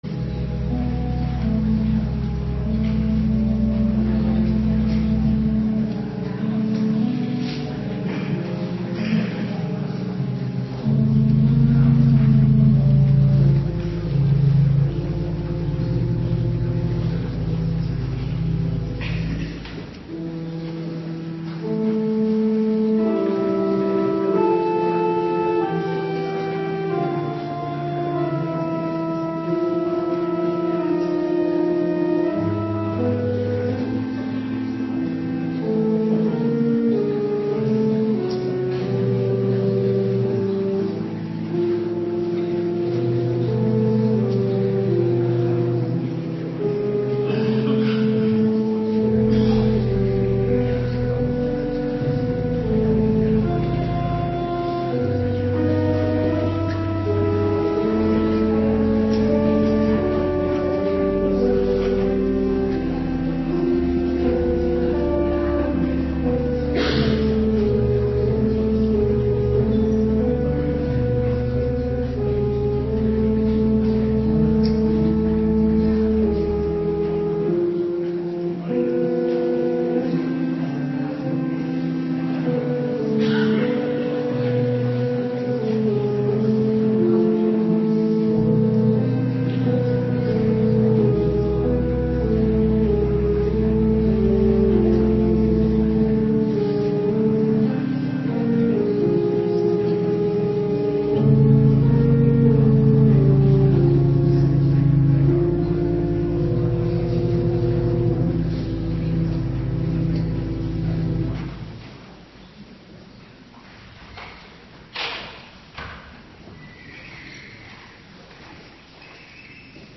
Avonddienst 3 april 2026